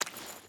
Water Chain Walk 5.wav